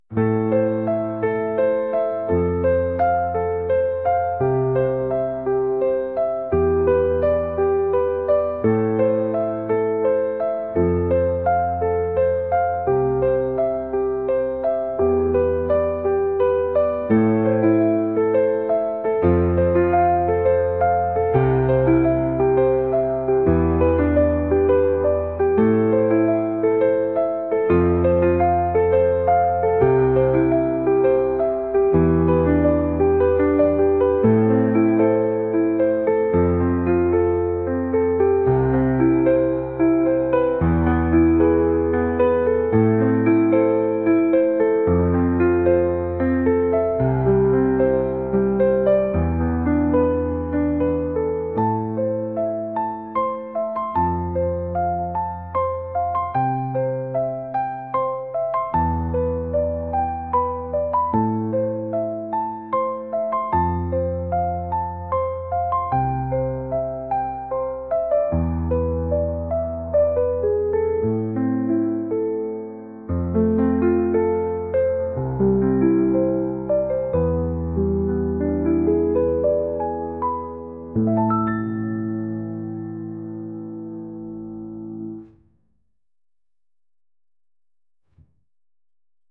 シリアス